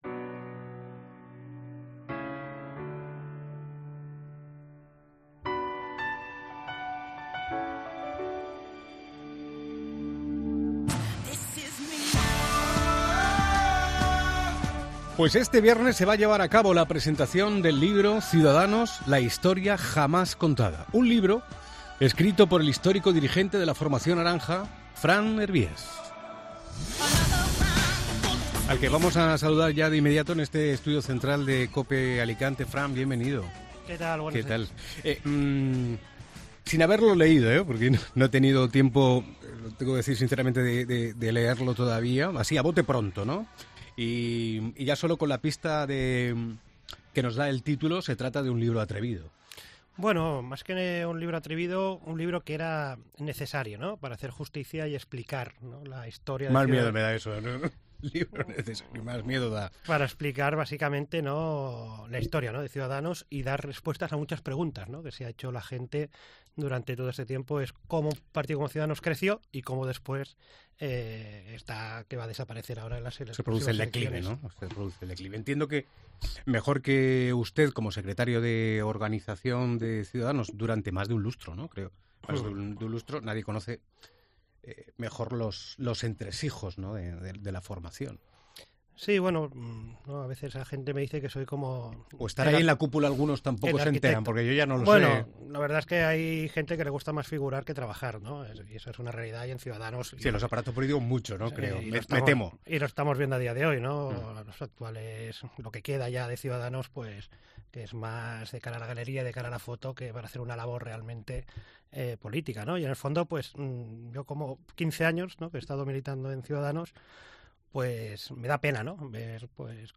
Entrevista a Fran Hervías